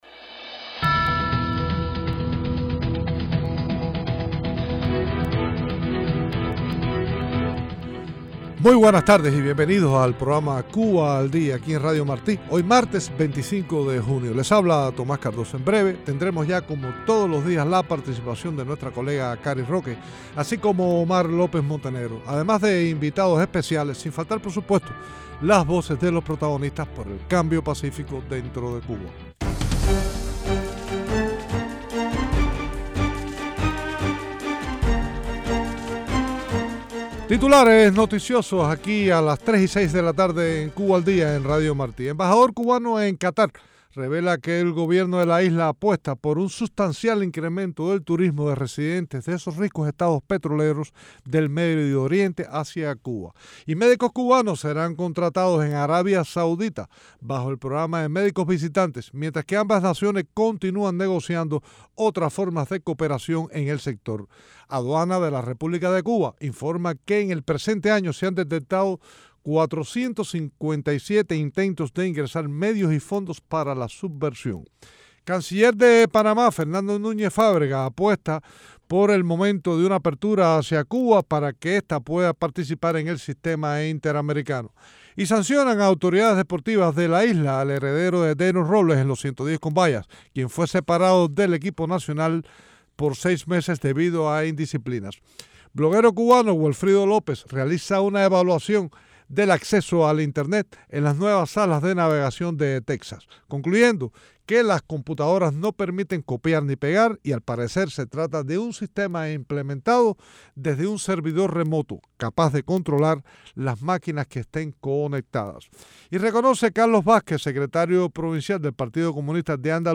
Berta Soler Dama de Blanco participan en el programa.